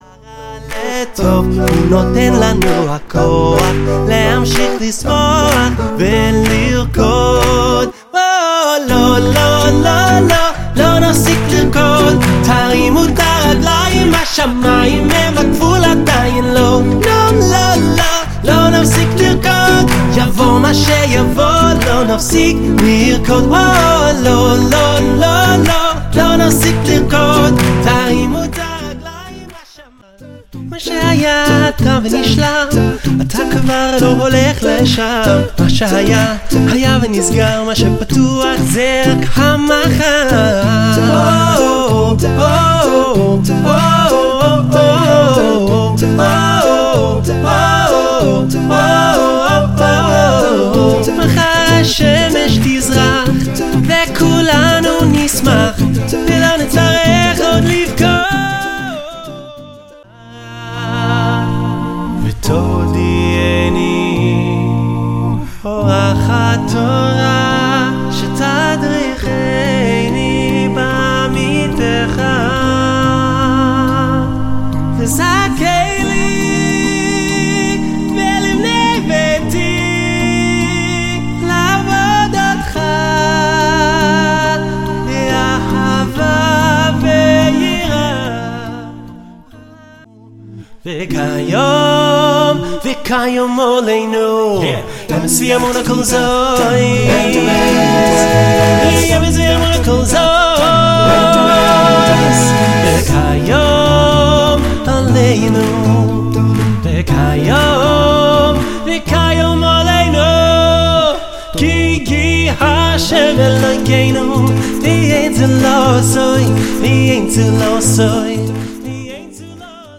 3. Acapella Jewish Music